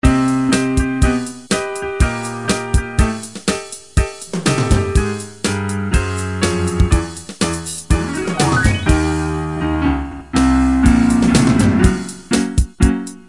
Tag: 歌曲 原创 音乐 钢琴 样品